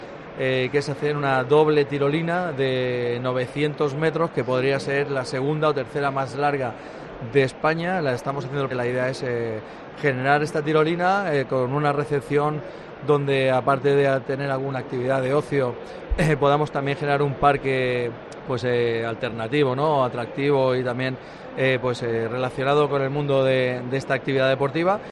Alejandro Morant explica en Mediodía COPE cómo será el proyecto de la tirolina de casi 1 kilómetro